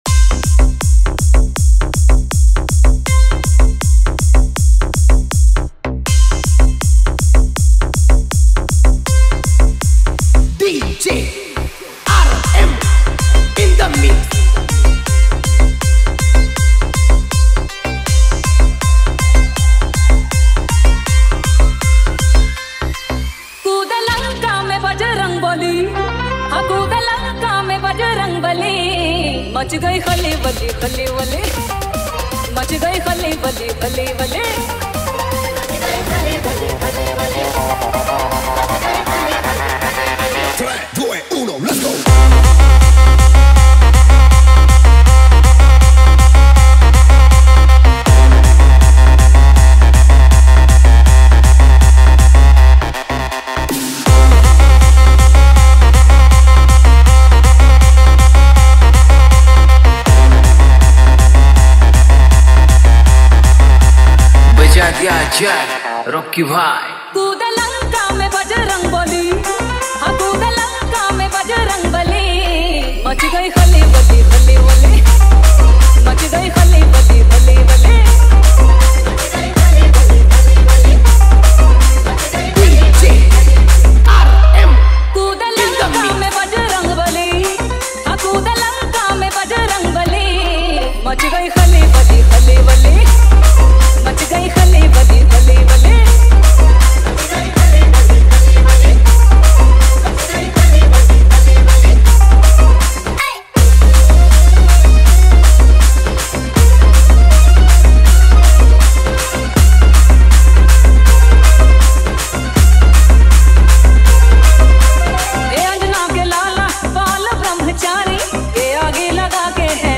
• Category: ODIA SINGLE REMIX